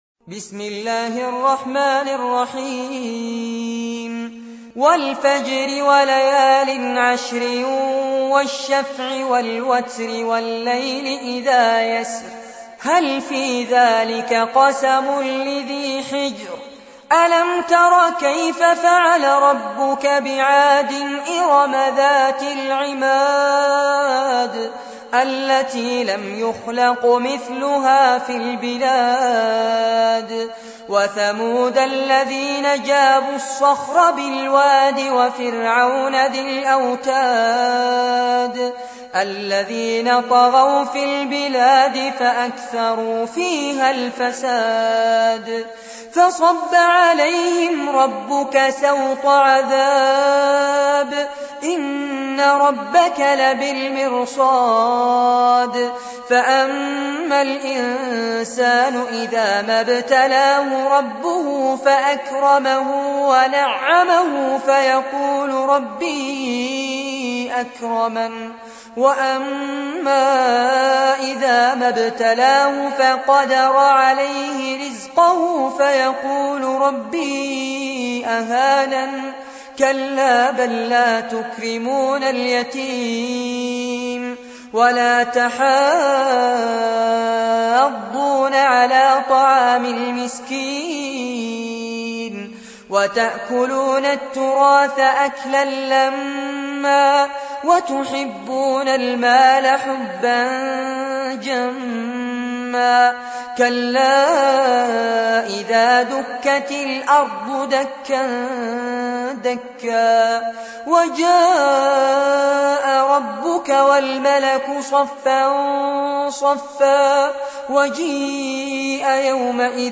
Récitation par Fares Abbad